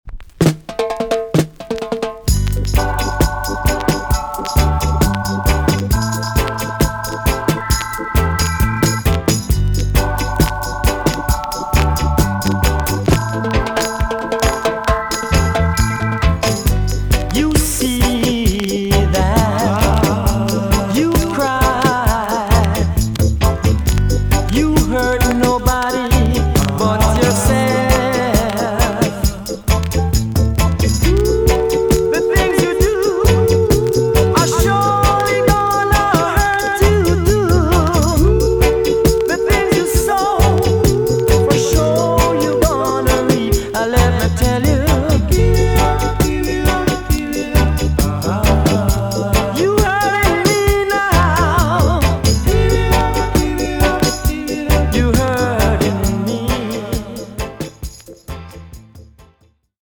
VG+ 少し軽いチリノイズがありますが良好です。